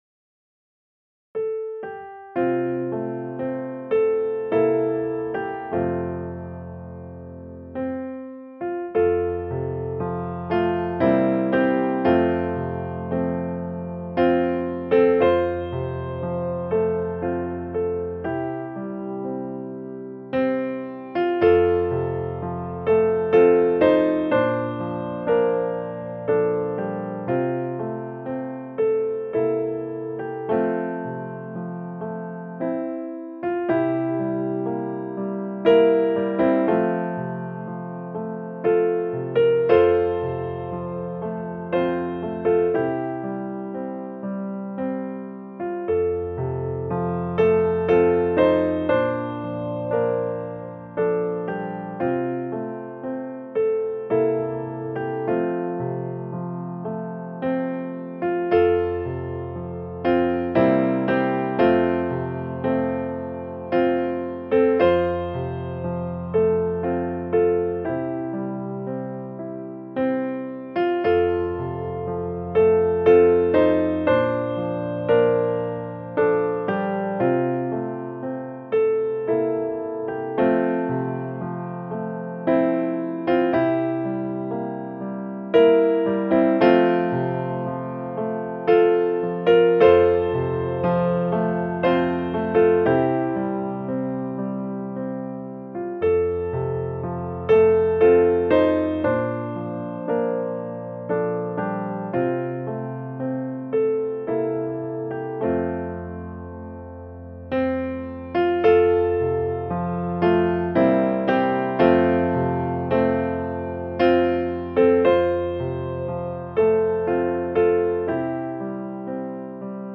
Piano
gospel hymn